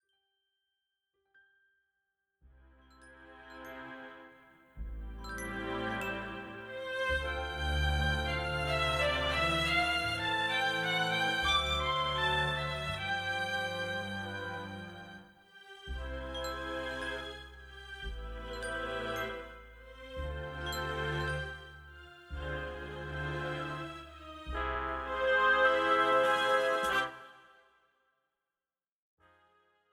This is an instrumental backing track cover.
• Without Backing Vocals
• No Fade